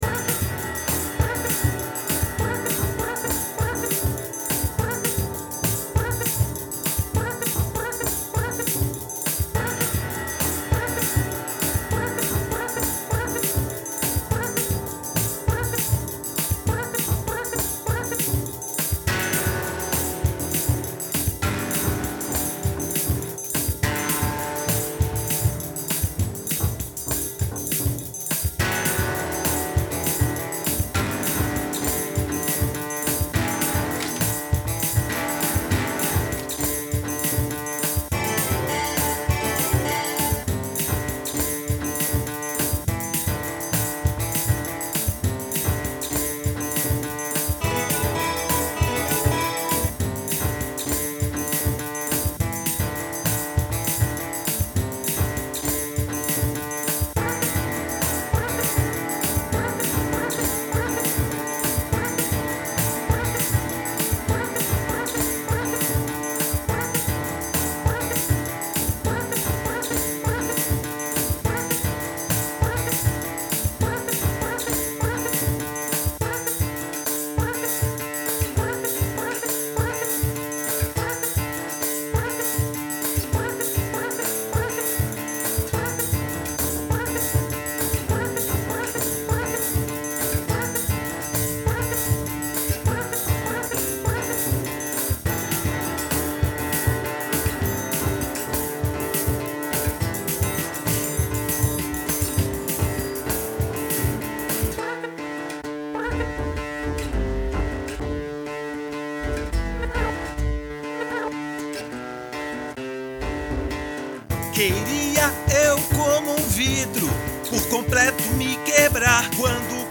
EstiloGótico